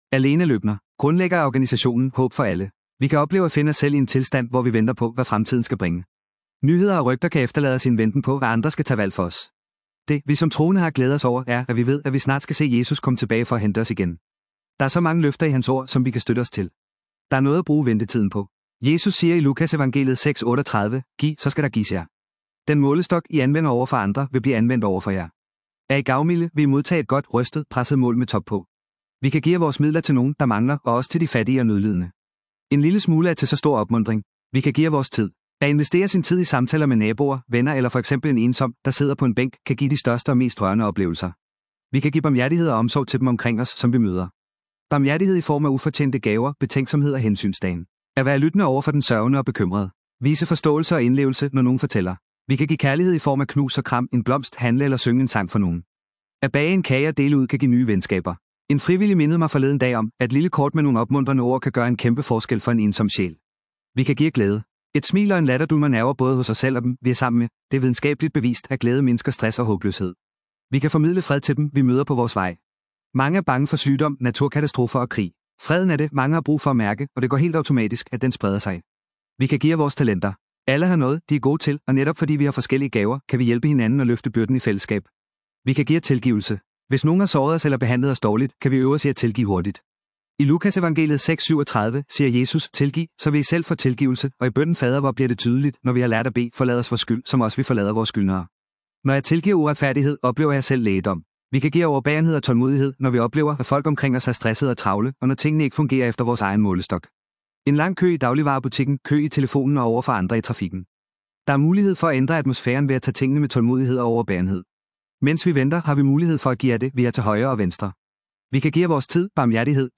Ugens Prædiken